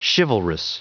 Prononciation du mot chivalrous en anglais (fichier audio)
Prononciation du mot : chivalrous